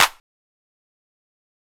Waka Clap - 2 (1).wav